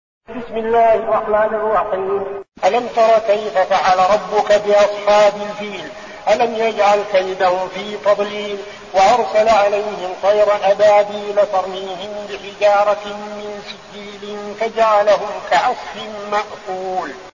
أحد أشهر قراء القرآن الكريم في العالم الإسلامي، يتميز بجمال صوته وقوة نفسه وإتقانه للمقامات الموسيقية في التلاوة.
تلاوات المصحف المجود